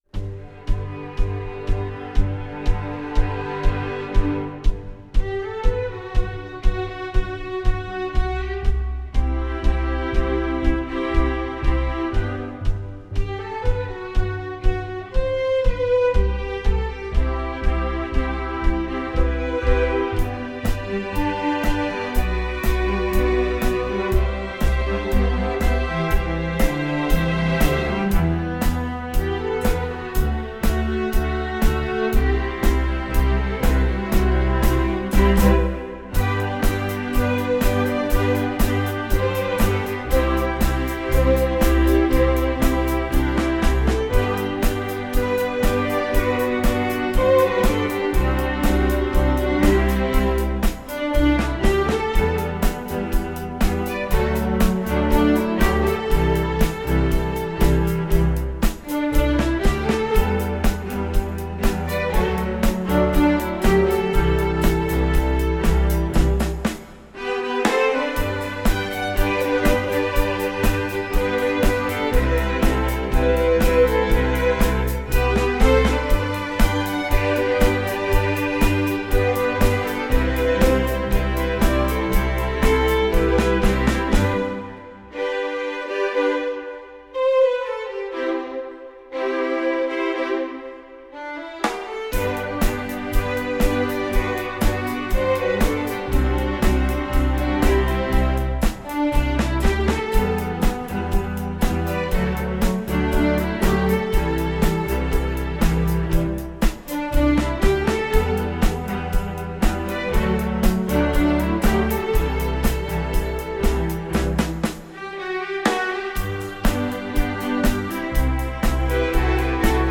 String Orchestra (ME)   Score